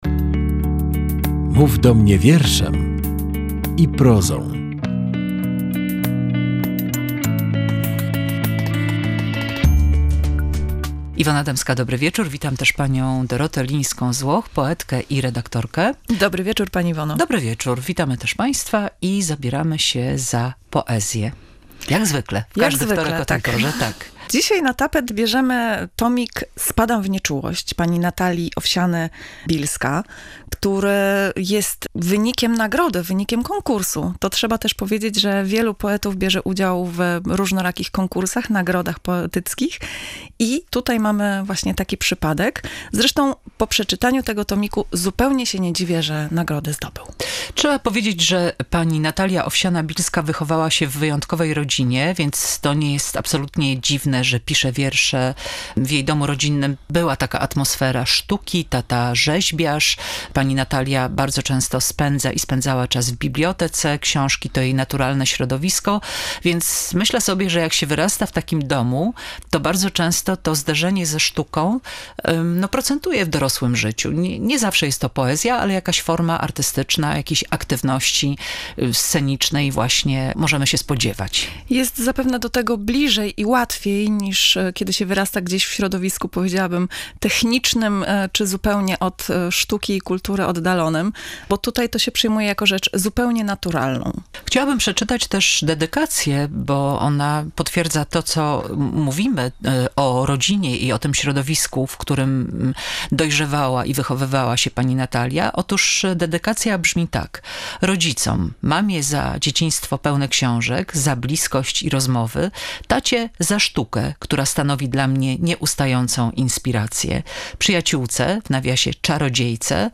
Samotność i egzystencjalne zawieszenie. Rozmowa wokół tomu poetyckiego „Spadam w nieczułość”